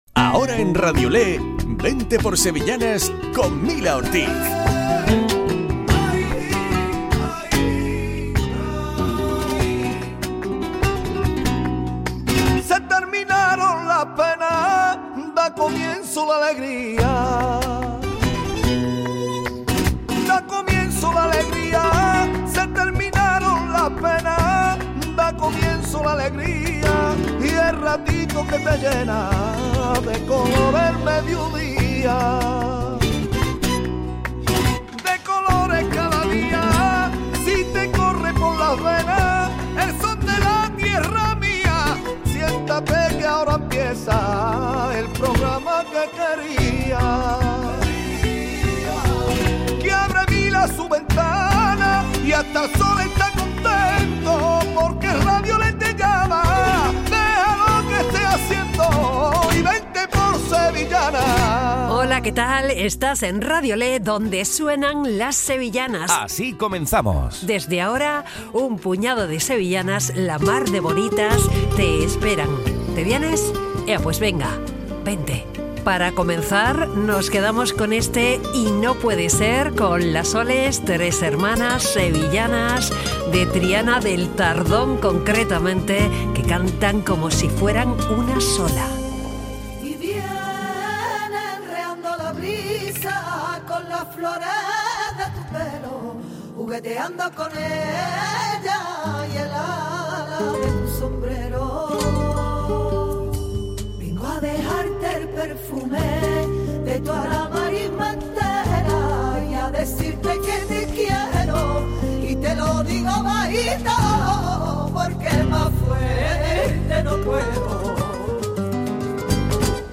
Programa dedicado a las sevillanas.